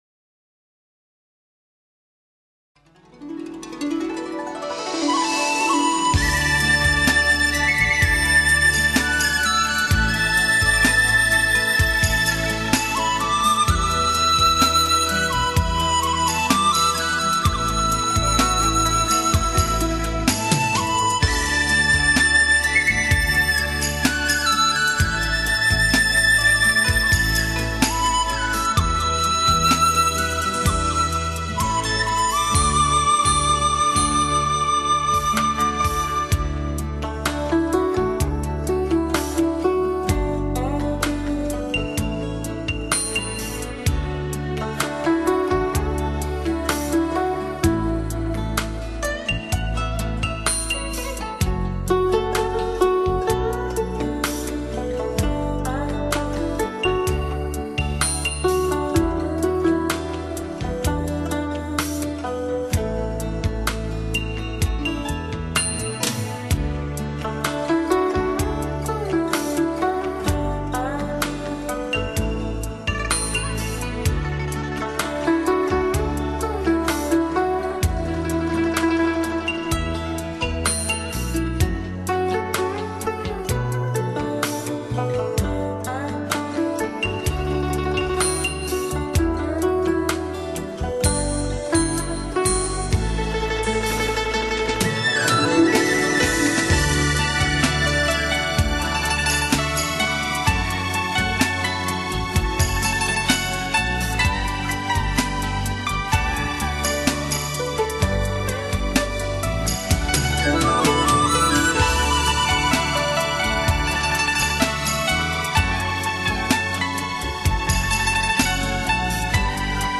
心头只留下一片宁静…